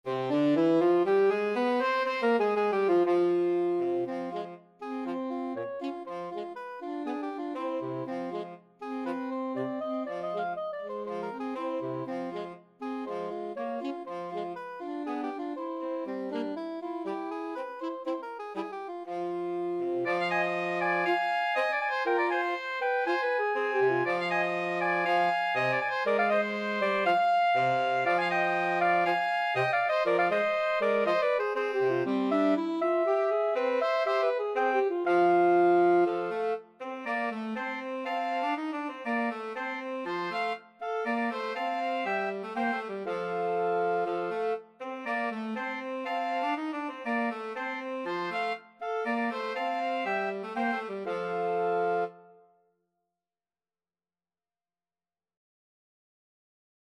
Free Sheet music for Woodwind Trio
Soprano SaxophoneAlto SaxophoneTenor Saxophone
Traditional Music of unknown author.
C minor (Sounding Pitch) (View more C minor Music for Woodwind Trio )
Allegro (View more music marked Allegro)
2/4 (View more 2/4 Music)